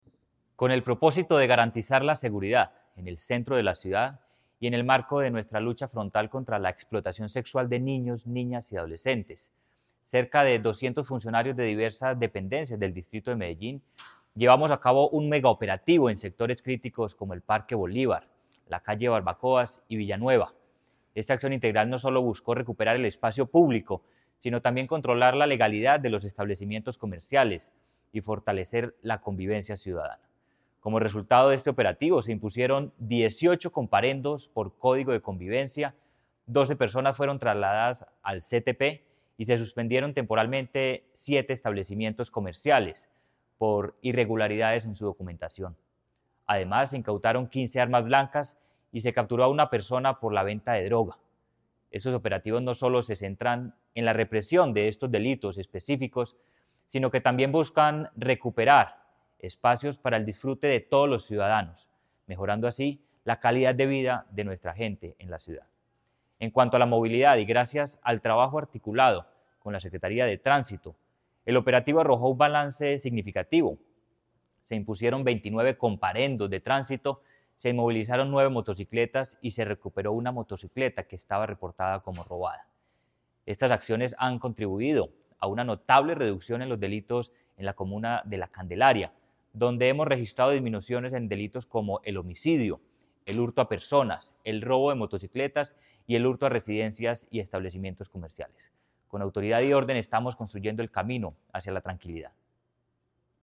Palabras de Manuel Villa Mejía, secretario de Seguridad y Convivencia